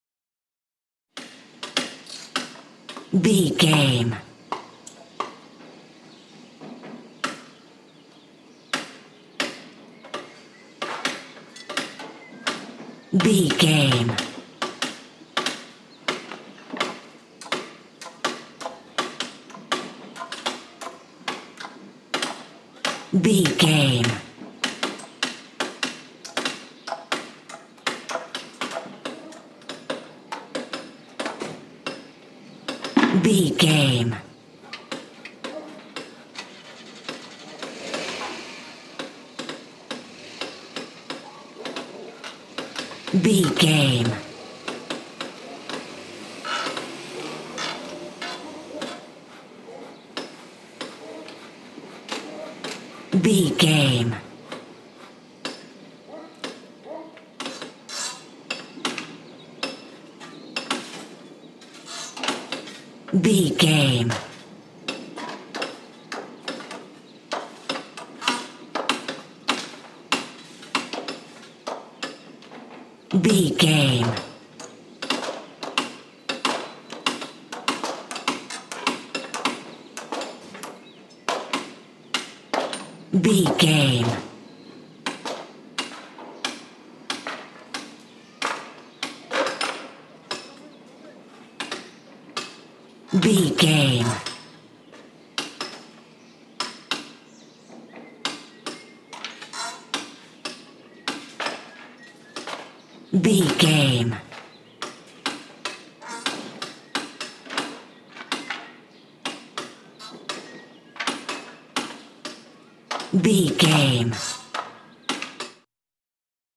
Construction ambience 15 | VGAME
Construction ambience
Sound Effects
urban
backgrounds